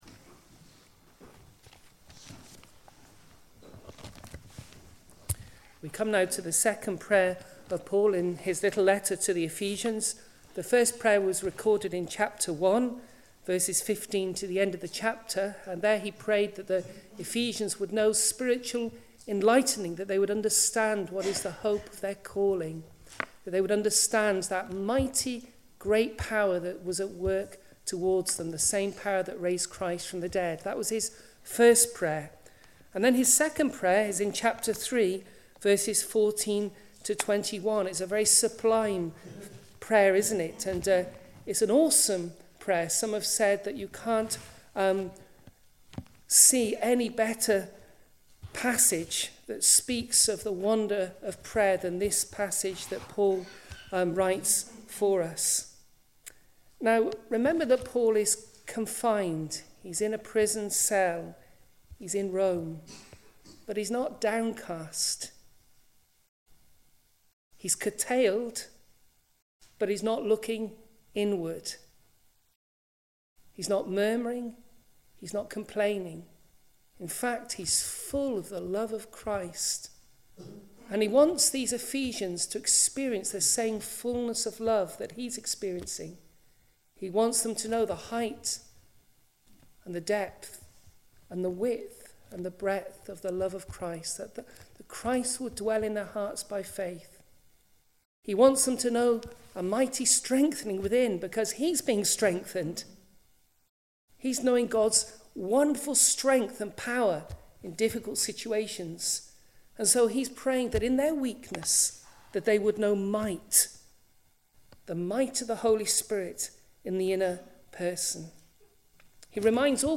Passage: Ephesians 3:14-21 Service Type: Sunday Evening